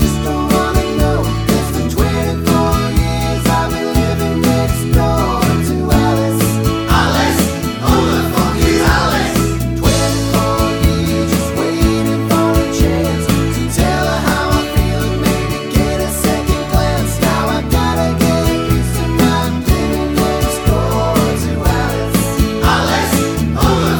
Highly Offensive Lyrics Comedy/Novelty 3:39 Buy £1.50